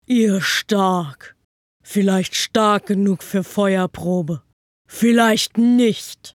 Synchron – RPG – Big Creature